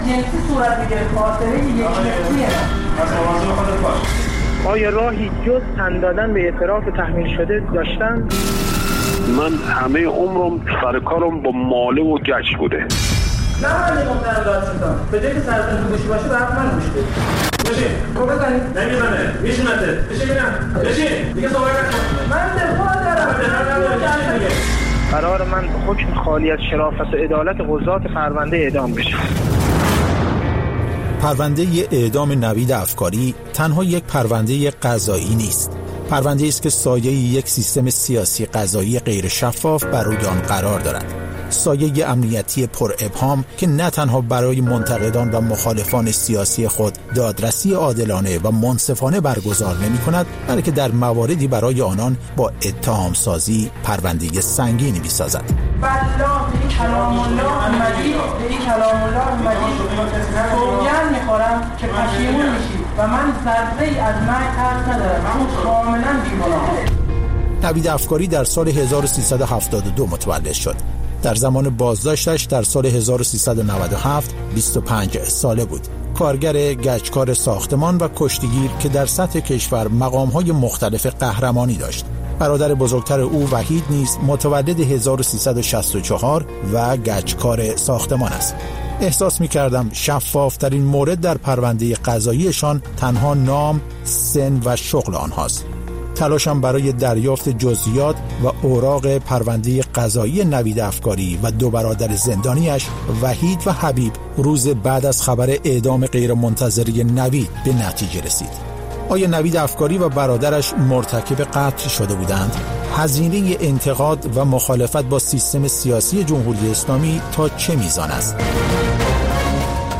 بازپخش مستند رادیویی: «پرونده نوید افکاری»